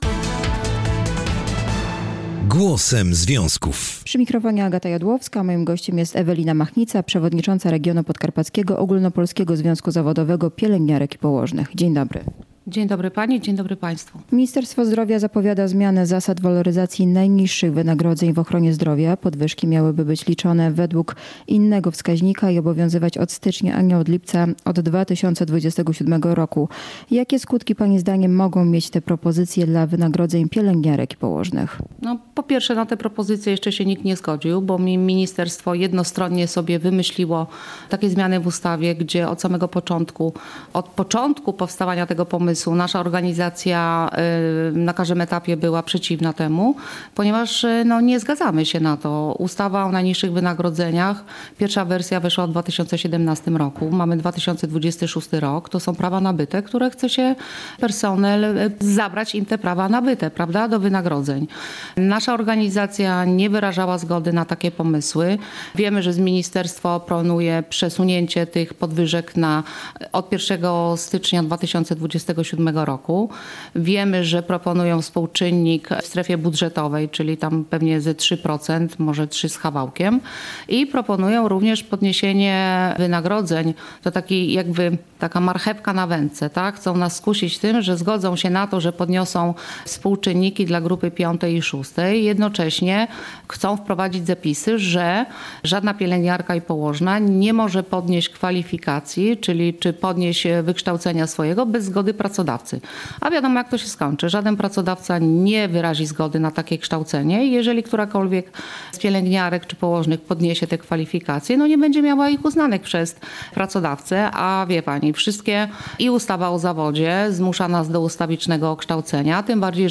Głosem związków • Pielęgniarki i położne sprzeciwiają się zapowiadanym zmianom w zasadach waloryzacji najniższych wynagrodzeń w ochronie zdrowia. O swoich zastrzeżeniach mówiła na antenie Polskiego Radia Rzeszów